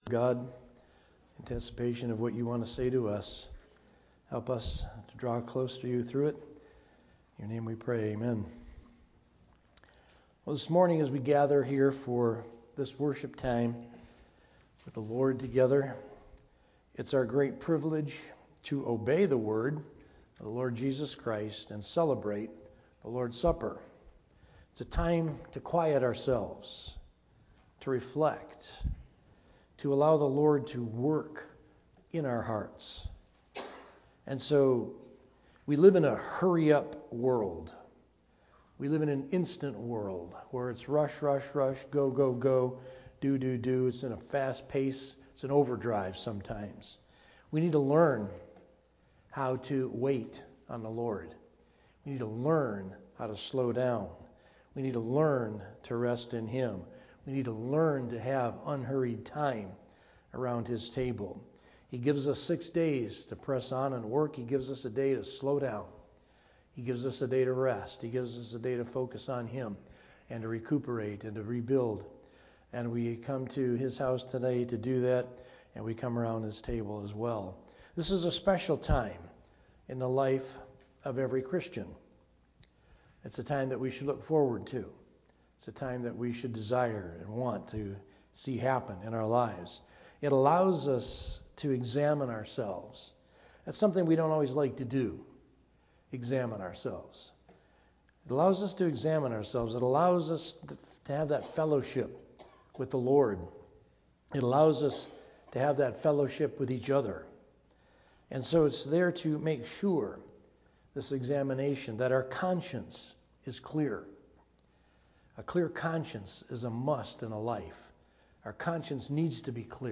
From Series: "Sunday Morning - 11:00"